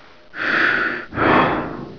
gasm_breath1.wav